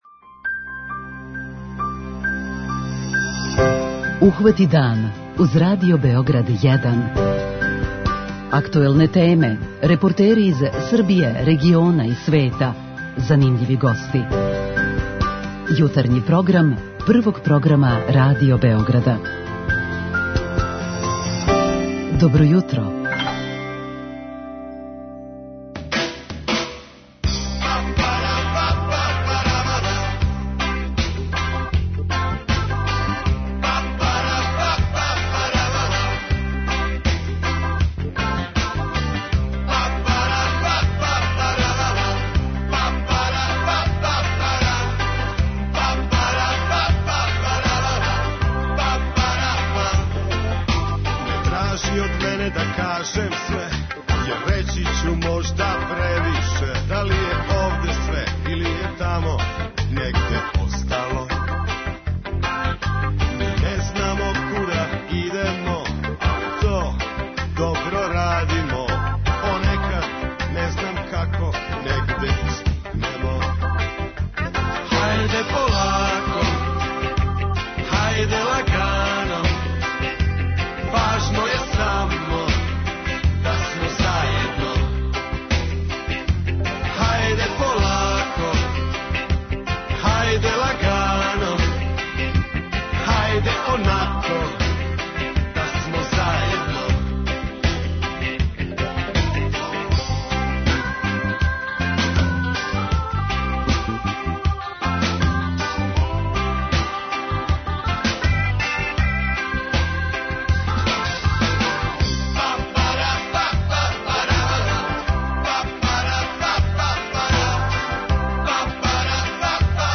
А о тротинетима ћемо разговарати с гостом у студију.
О овој теми претходно ћемо разговарати и са слушаоцима у редовној рубрици "Питање јутра". Говорићемо и о томе како помоћи повратницима из иностранства да се укључе у друштво.